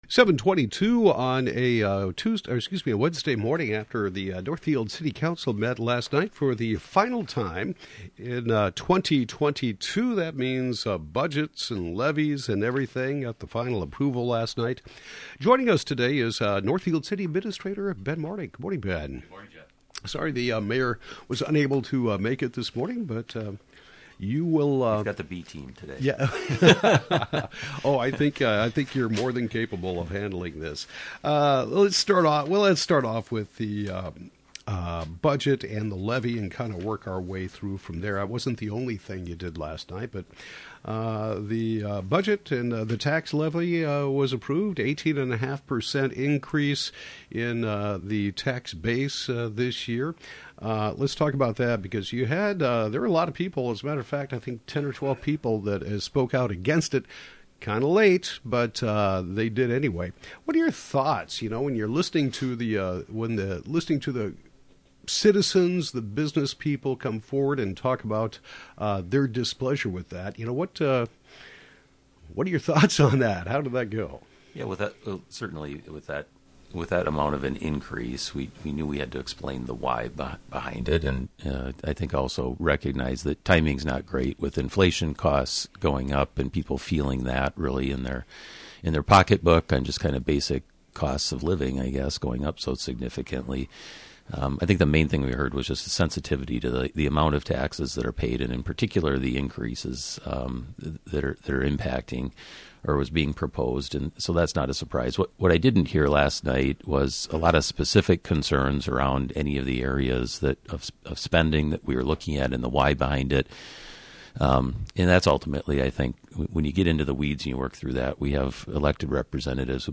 Northfield City Administrator Ben Martig discusses the December 6 City Council meeting. Topics include public comments about tax increase, approval of budget items, discussion of street projects, and much more.